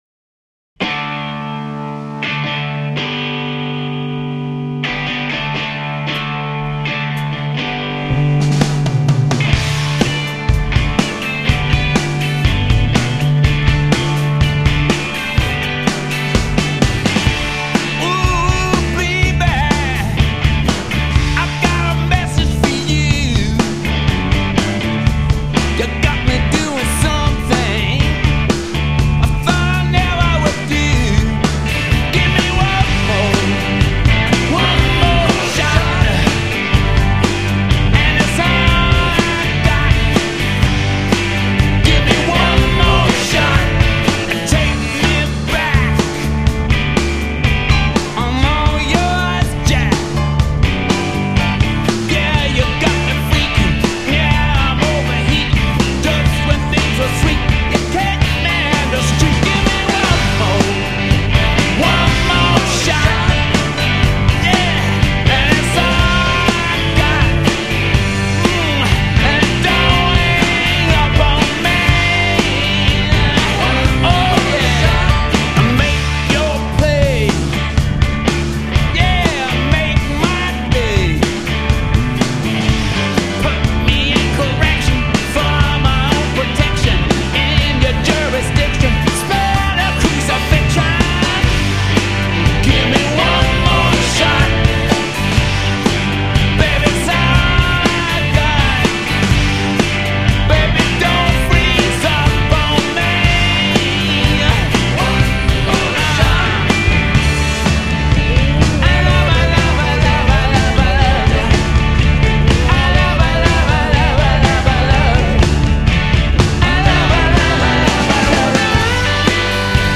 classic-sounding